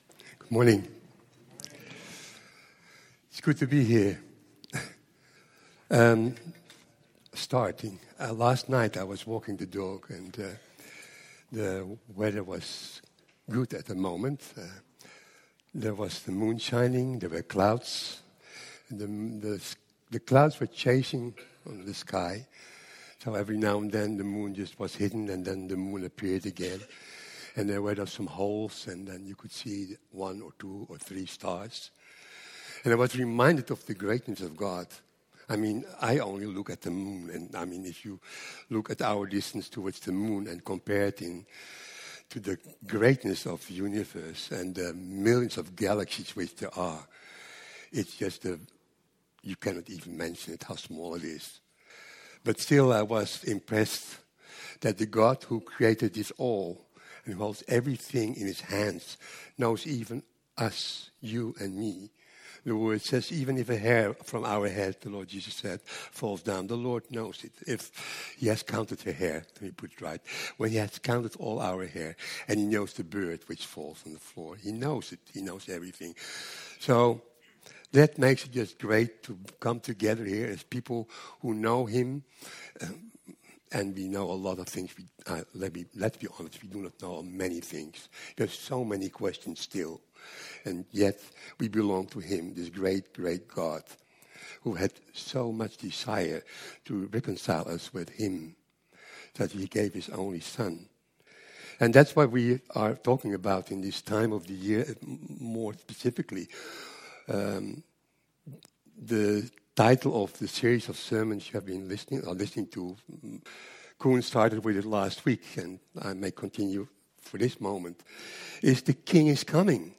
Vineyard Groningen Sermons